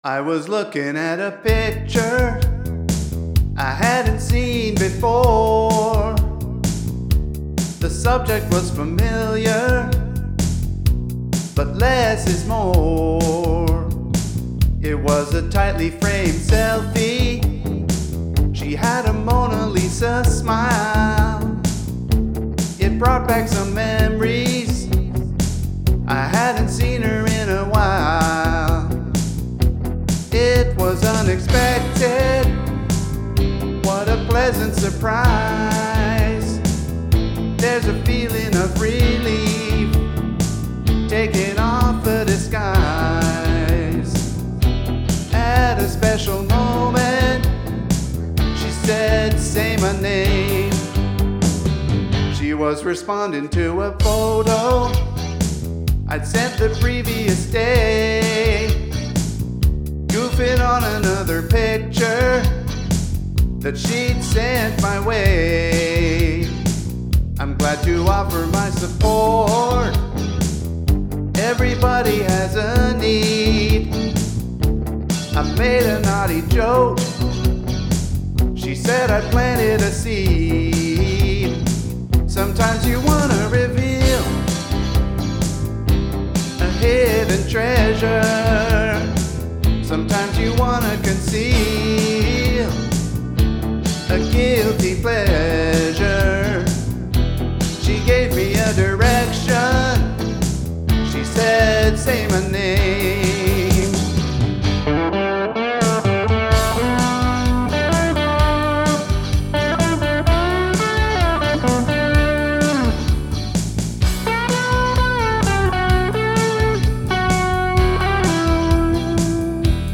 Say My Name March 2024May 2024 Here's this year's Victoria Day weekend song to mark the anniversary of my ongoing home recording project.